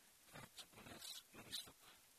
Pronunciation: ka:tʃəpu:nəs-ministuk